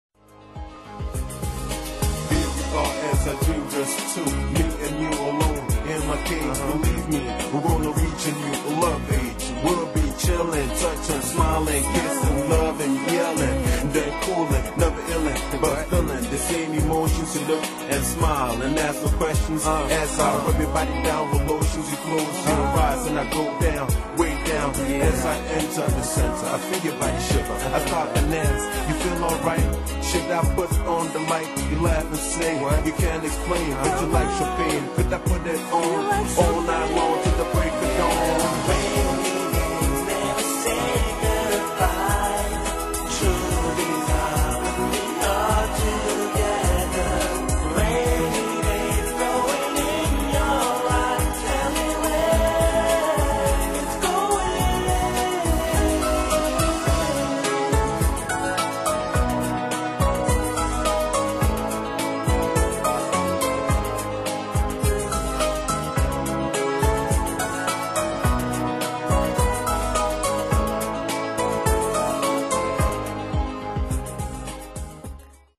Pop-Mix